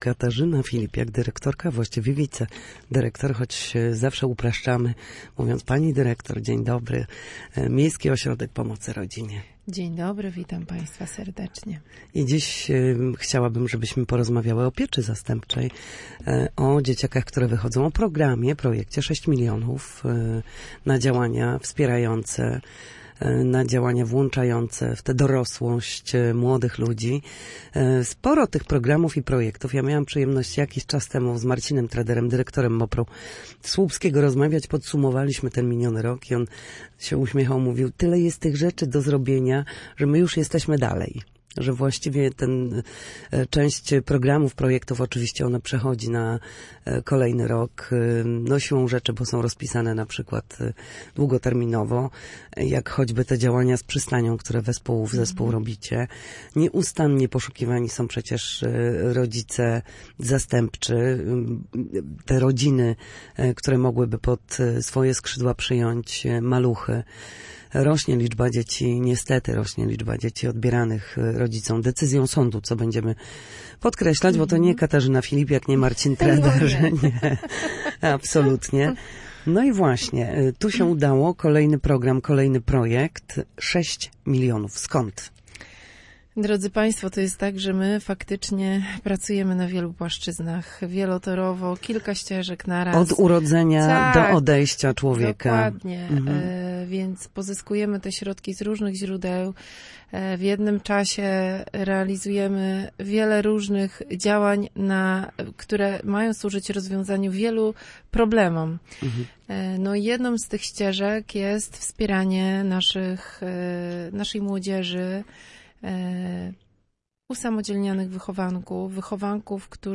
Na naszej antenie mówiła o projekcie przeznaczonym na usamodzielnienie młodzieży z pieczy zastępczej i ośrodków podlegających instytucji.